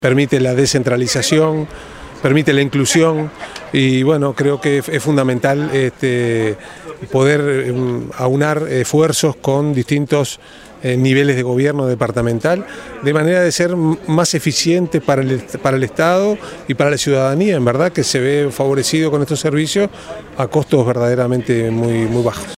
presidente_del_correo_rafael_navarrine.mp3